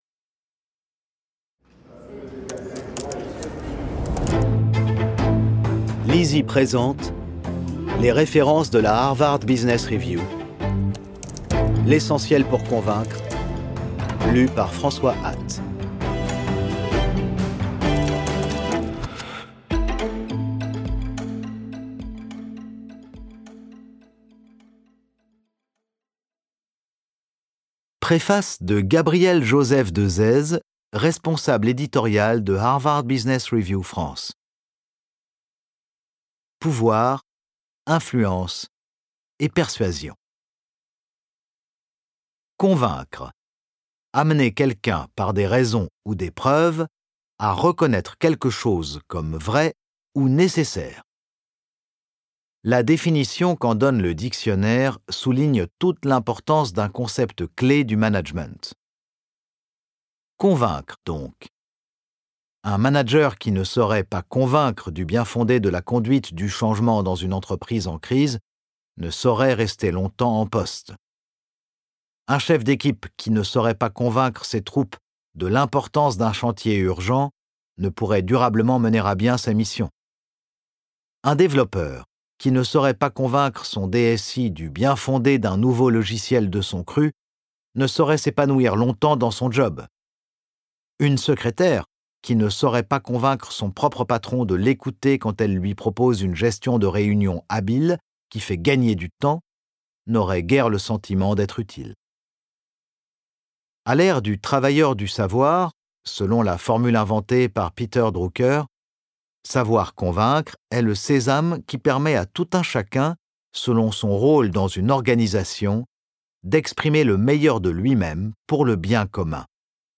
Click for an excerpt - L'Essentiel pour convaincre de Harvard Business Review